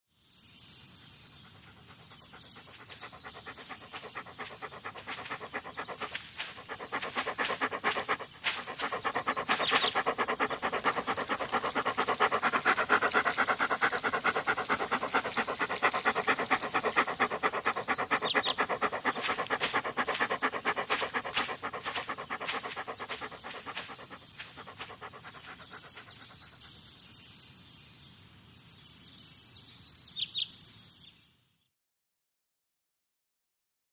Peeing, Dog | Sneak On The Lot
Dog Pants, Paws Walking On Leaves, Up To Tree To Urinate And Away.